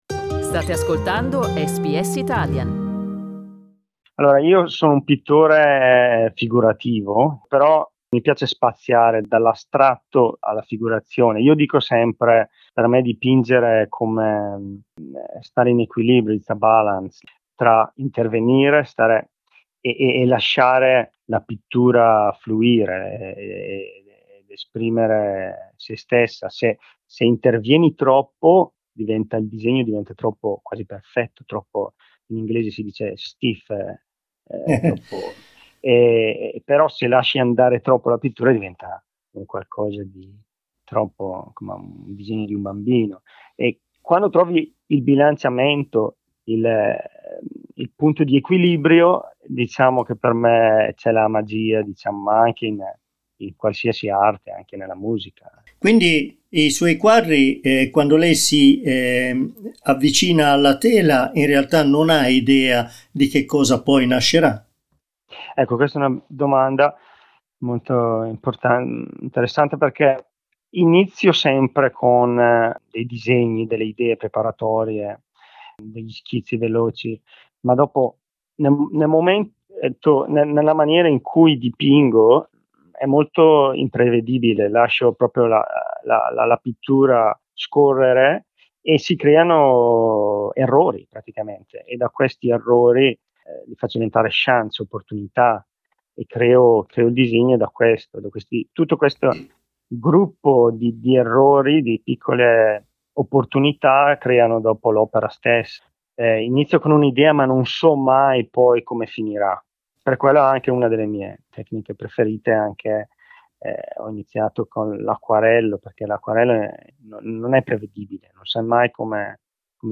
Riascolta l'intervista integrale